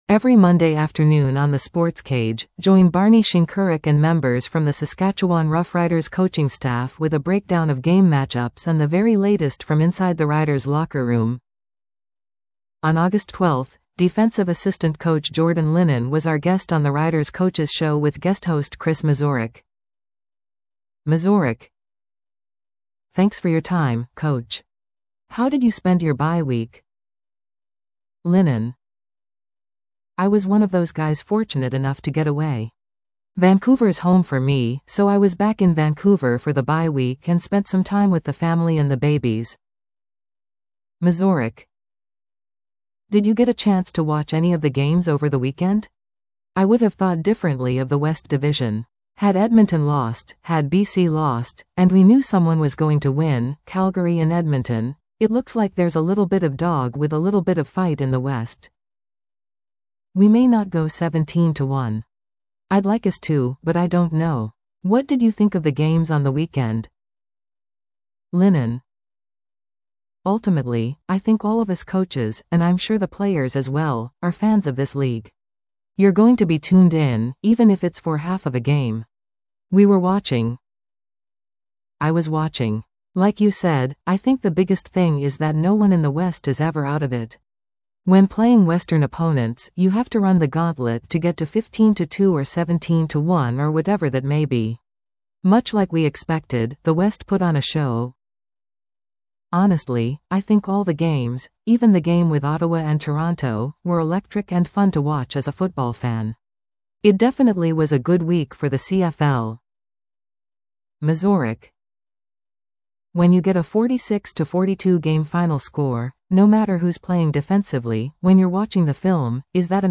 was our guest on the Riders Coaches Show with guest host